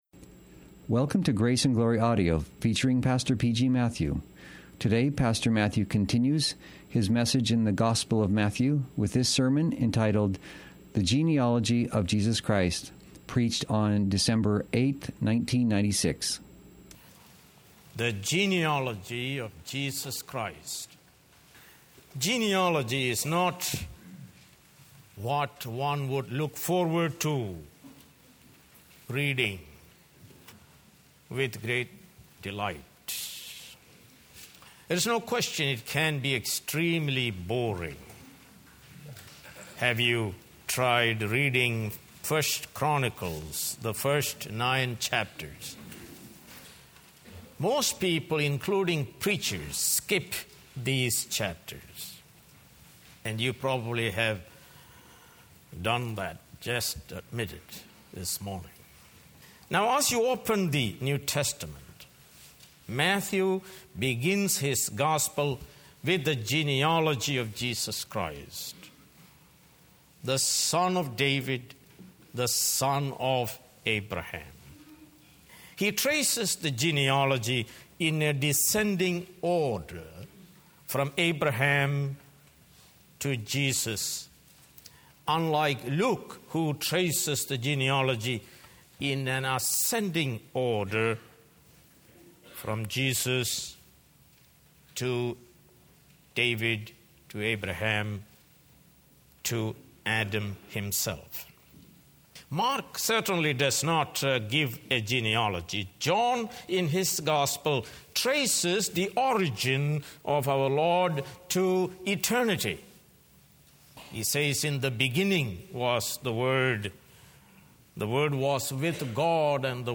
Sermons | Grace Valley Christian Center | Page 2